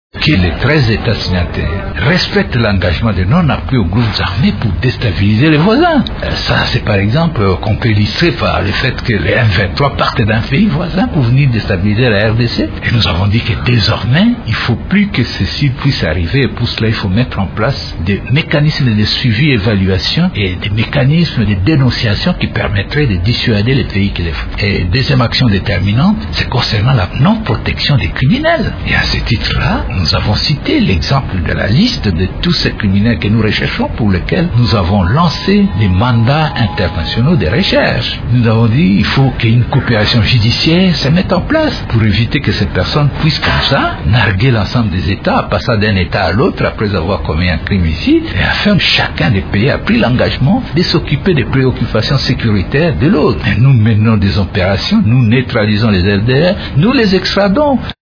Dans une interview accordée à Radio Okapi, le général Denis Kalume Numbi s’est dit tout de même inquiet de constater que, malgré les efforts consentis par son pays, la sécurité demeure menacée dans la région des Grand Lacs.